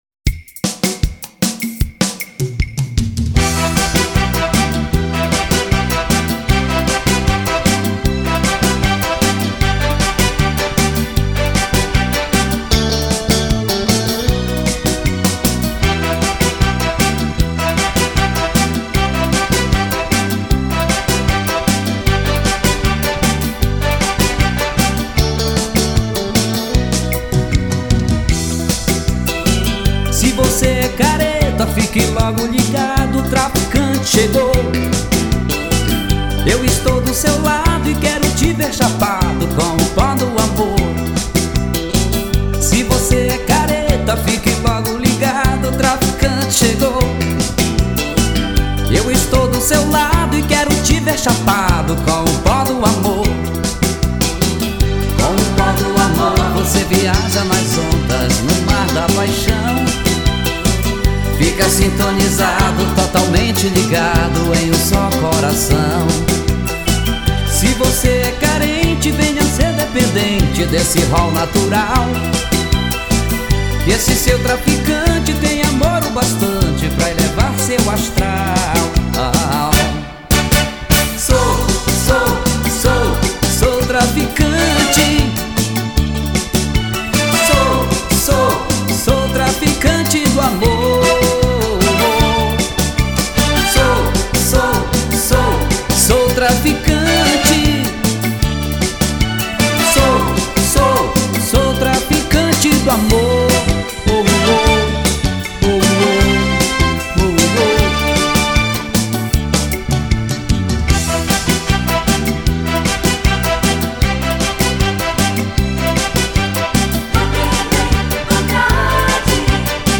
<< | Atual | >> Estilo: Brega.